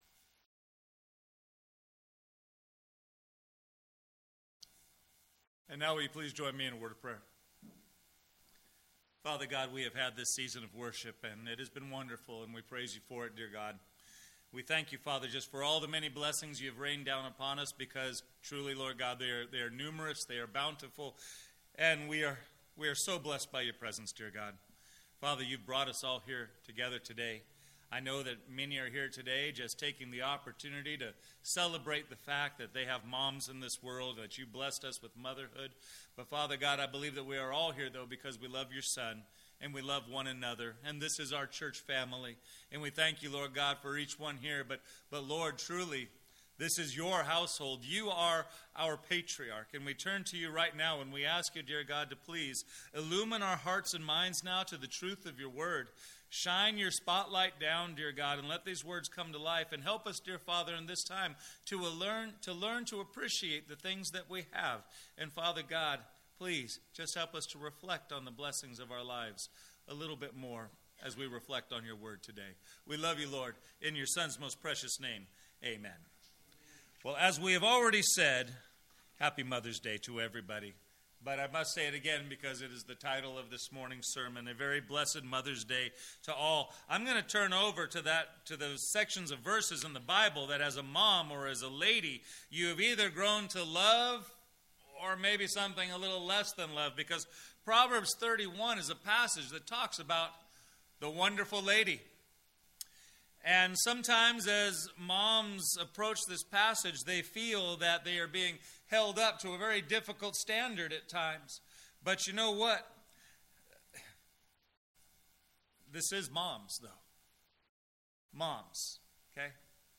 Sermons - First Baptist Church Solvang